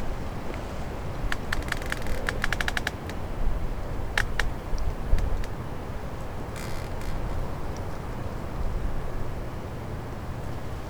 woodCreaking.wav